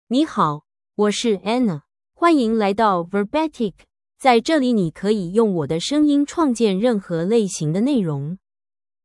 Anna — Female Chinese AI voice
Anna is a female AI voice for Chinese (Mandarin, Traditional).
Voice sample
Listen to Anna's female Chinese voice.
Female
Anna delivers clear pronunciation with authentic Mandarin, Traditional Chinese intonation, making your content sound professionally produced.